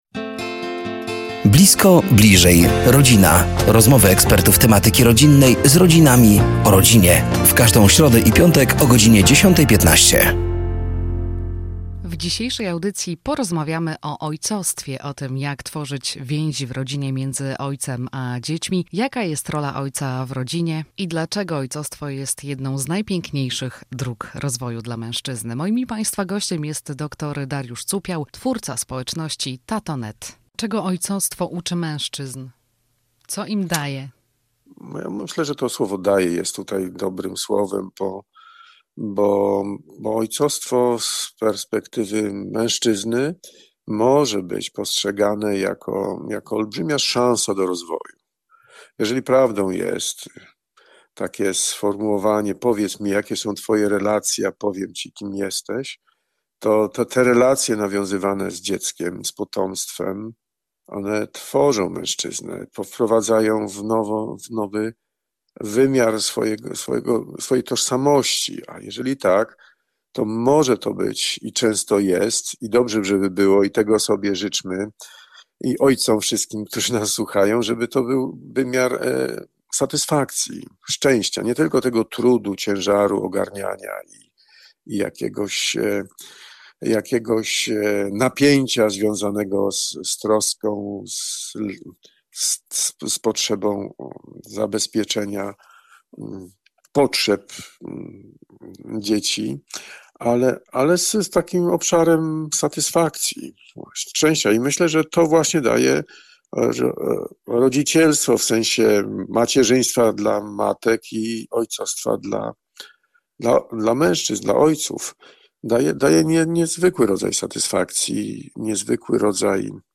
Do studia zaproszeni są eksperci w temacie rodziny i rodzicielstwa.